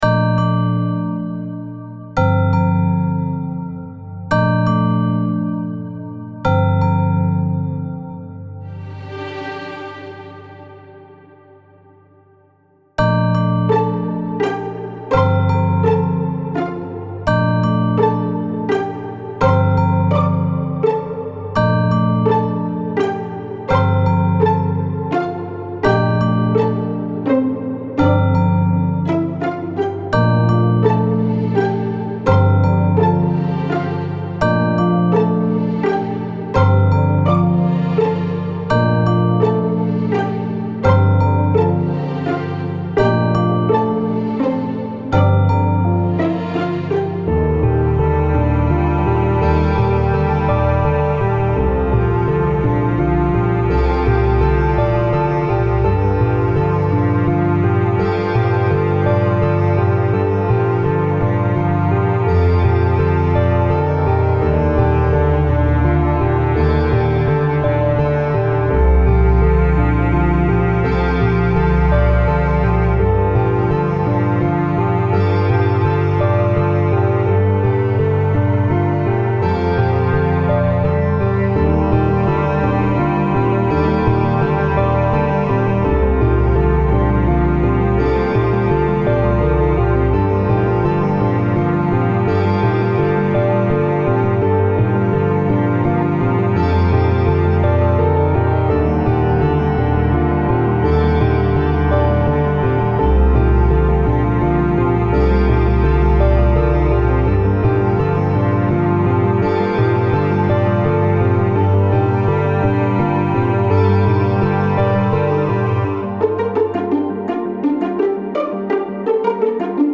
何時とも判らない霧雨の中、響き渡る鐘の音に導かれる
弦楽器や鐘、オルガンを重ねた荘厳なイメージの曲です